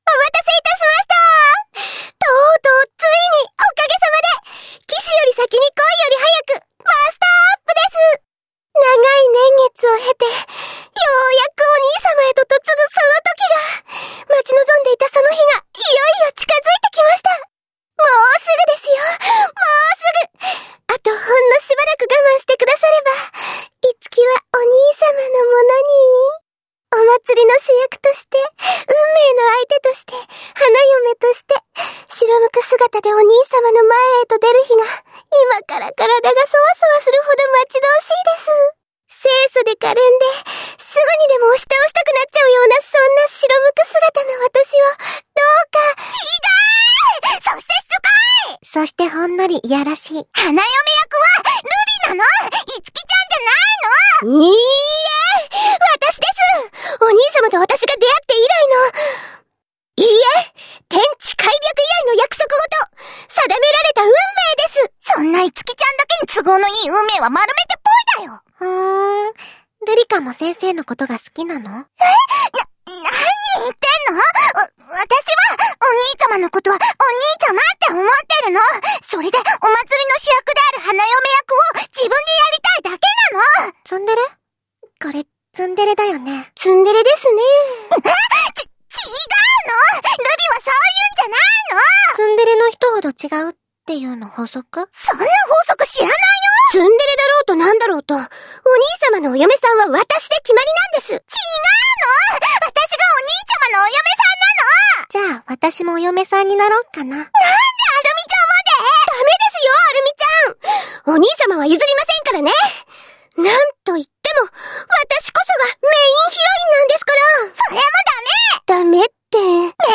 カウントダウン
三人そろってパパにご挨拶♪